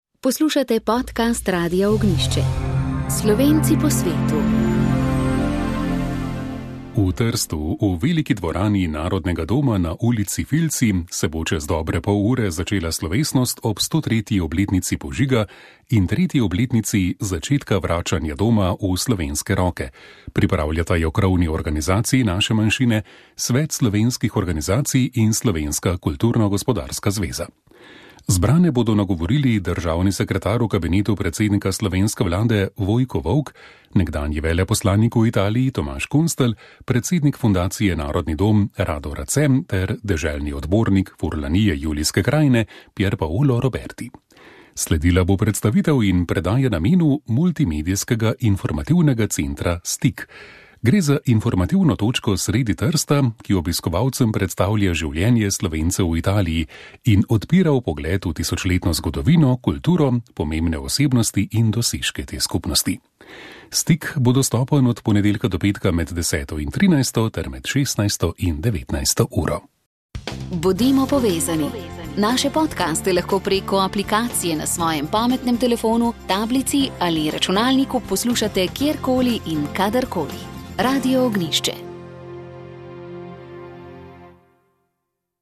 Sliko beneškega renesančnega slikarja Vittoreja Carpaccia, na kateri so Marija z detetom in svetniki, bodo prihodnji teden iz Padove vrnili v piransko samostansko cerkev Sv. Frančiška. Delo so iz Pirana odpeljali v času druge svetovne vojne, že leta pa potekajo aktivnosti, da bi umetniška dela, ki so bila na hranjenje odpeljana v Italijo, dobila svoj prvotni prostor na mestu za katerega so bila ustvarjena. Nekaj več o tem nam je povedal nekdanji veleposlanik pri Svetem sedežu Tomaž Kunstelj iz ministrstva za zunanje zadeve.